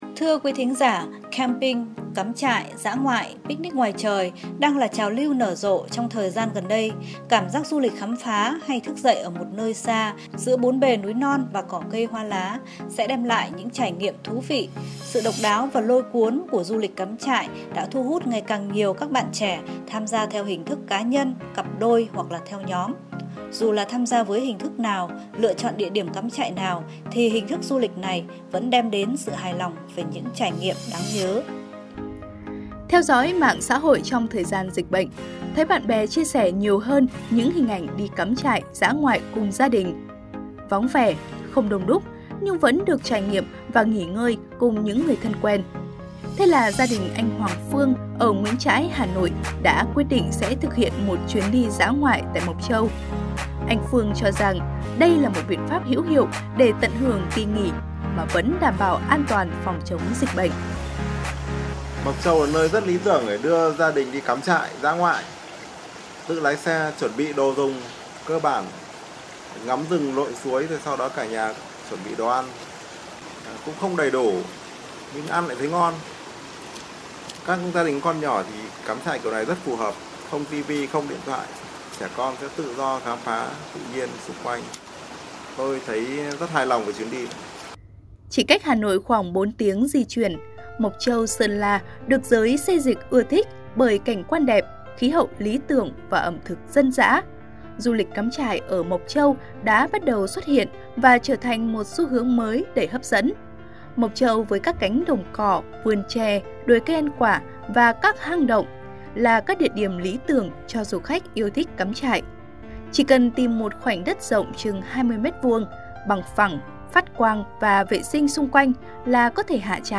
File phát thanh